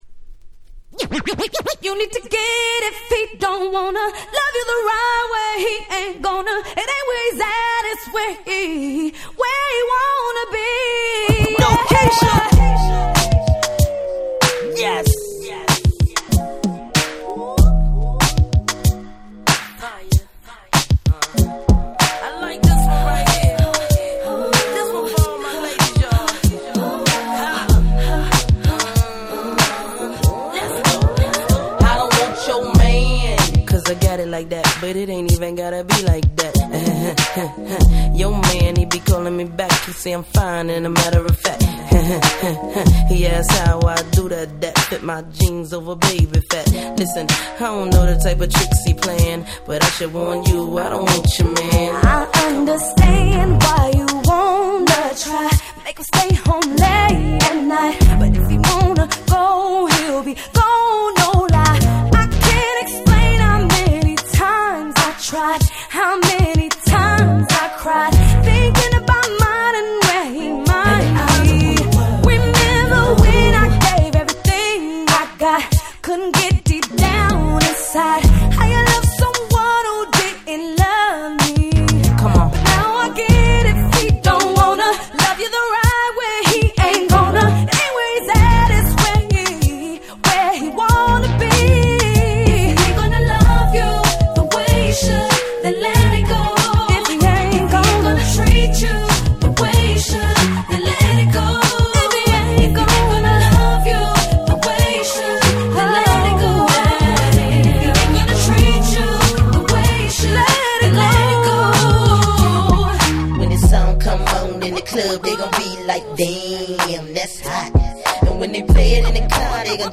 07' Smash Hit R&B !!